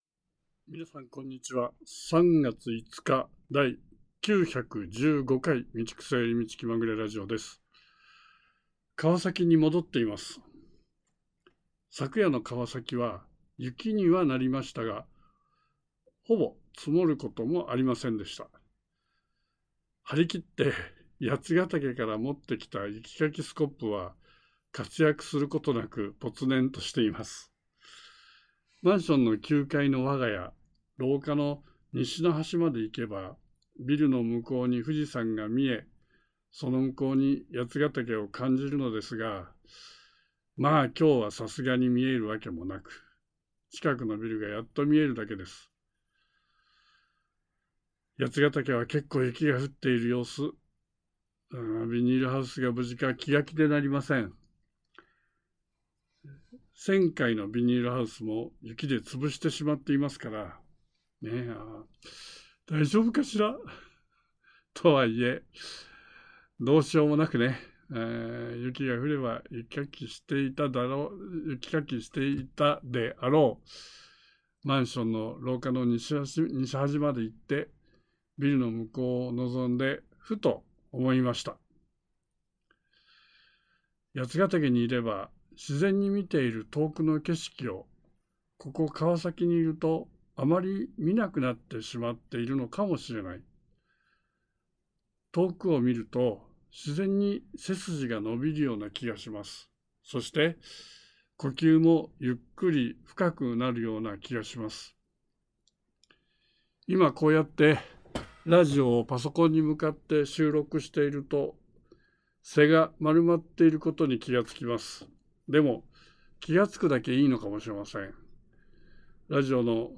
遠くを見ると、自然に背筋が伸びるような気がします そして、呼吸もゆっくり深くなるような気がします 今、こうやって、ラジオをパソコンに向かって収録していると、背が丸まっていることに気が付きます。